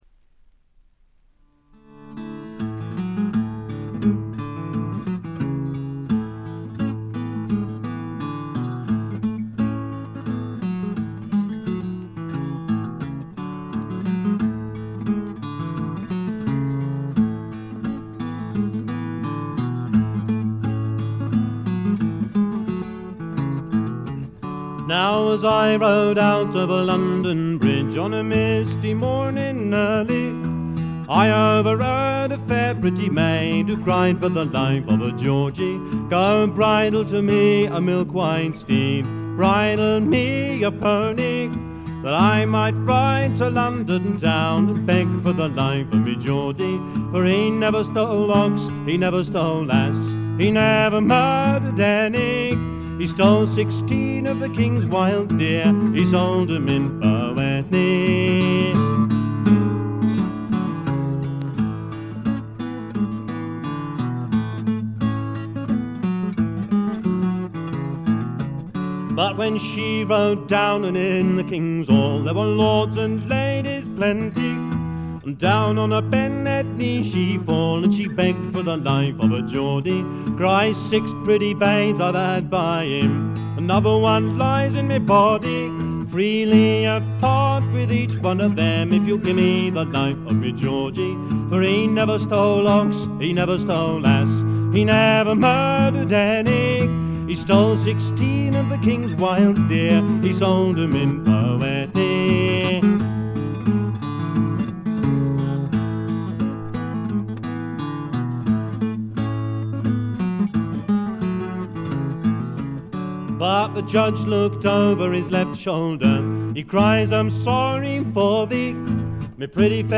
部分試聴ですが、軽微なチリプチが少し出る程度。
スイスのフォーク・フェスティヴァルの2枚組ライヴ盤。
試聴曲は現品からの取り込み音源です。